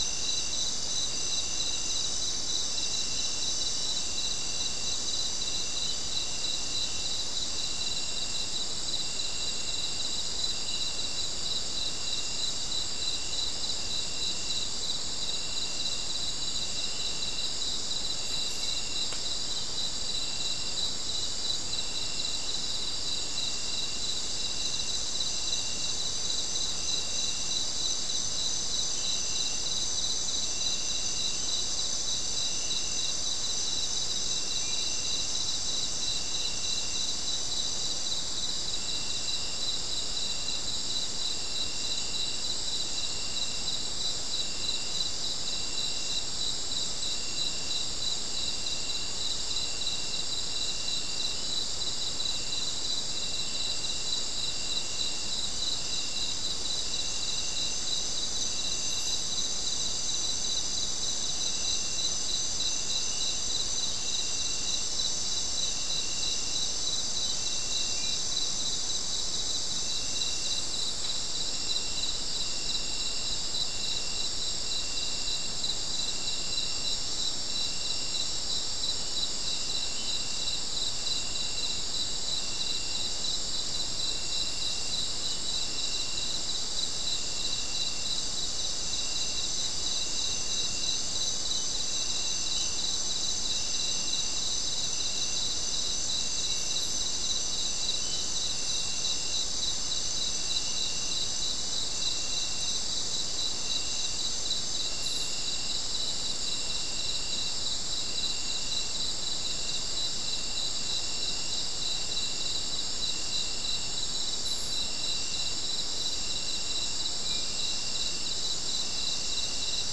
Soundscape
Location: South America: Guyana: Sandstone: 2
Recorder: SM3